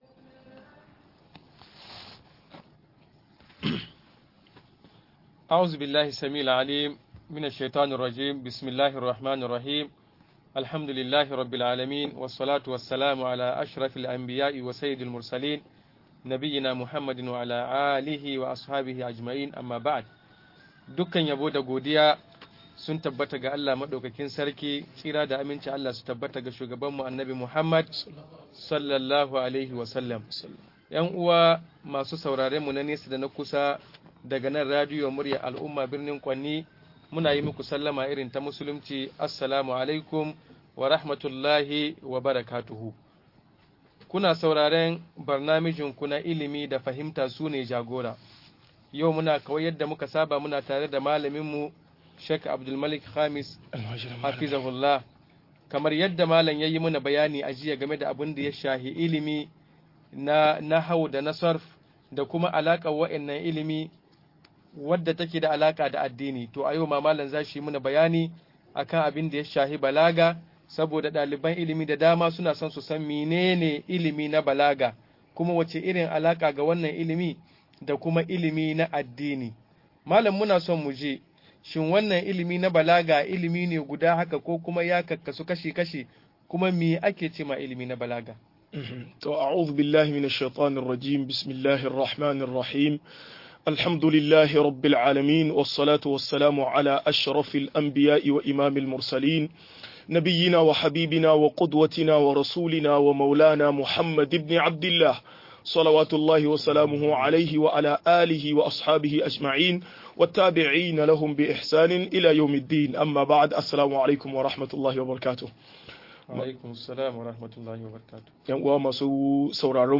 Balaga da alakar sa da shari'a - MUHADARA